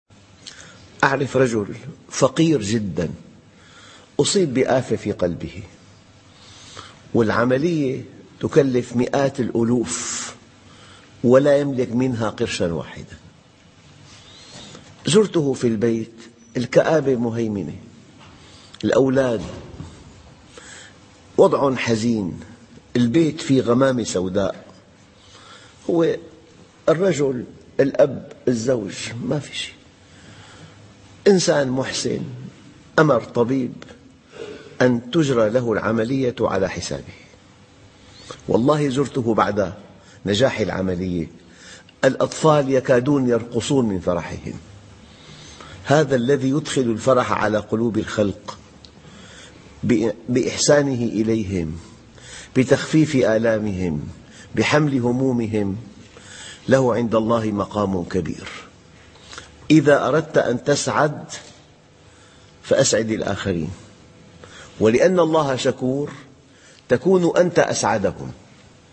كيف تصل إلى السعادة ؟؟؟ ......... درس مؤثّر ..........